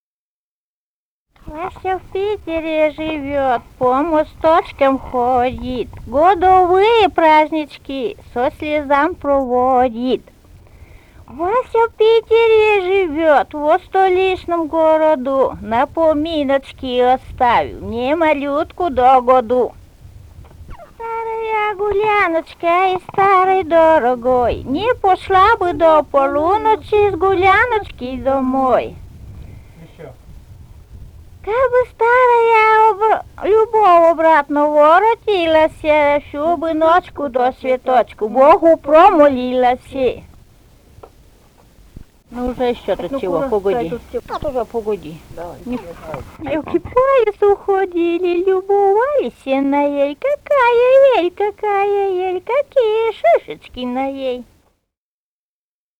Живые голоса прошлого 141. «Вася в Питере живёт» (частушки).